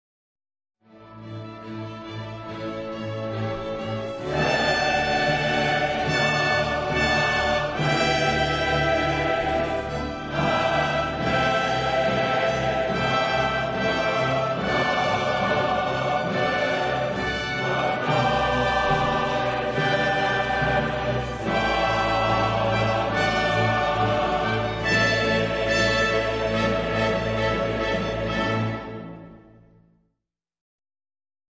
April 2002 Concert
Guest Conductor - Nicholas Cleobury
Guests - Huddersfield Choral Society